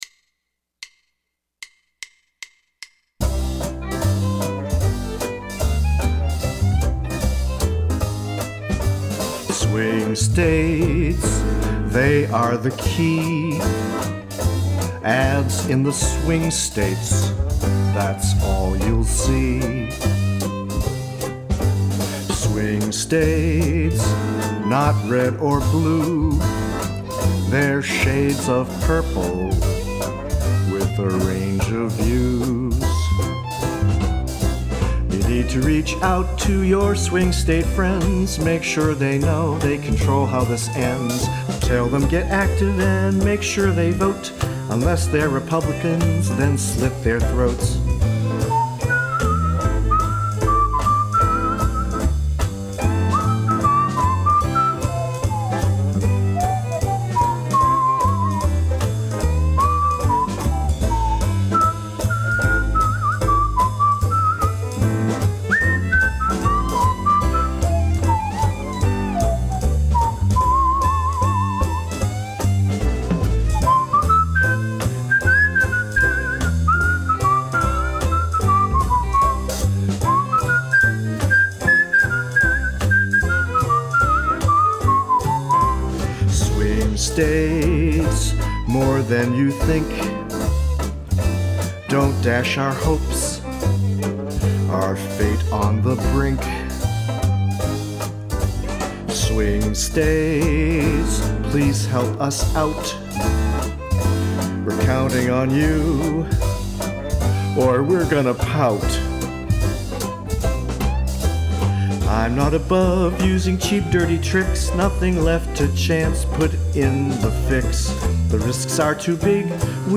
demo recording.